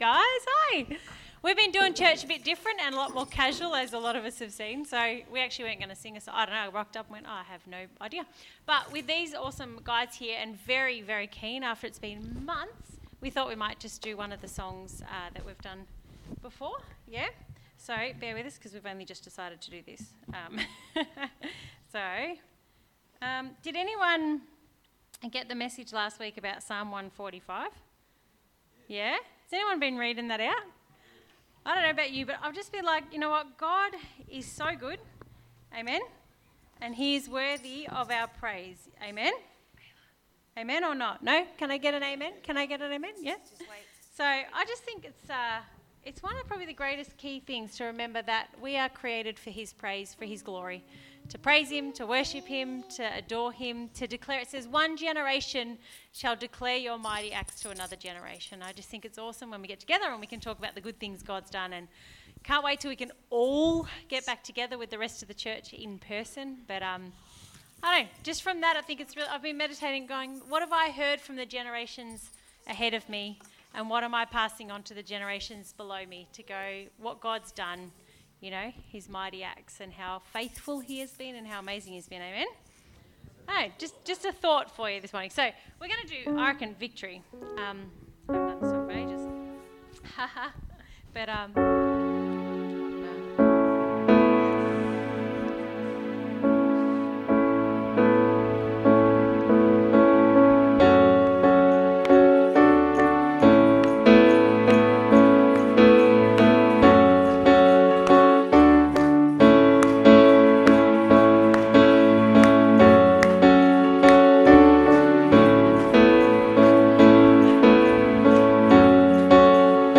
Sunday service 19th July 2020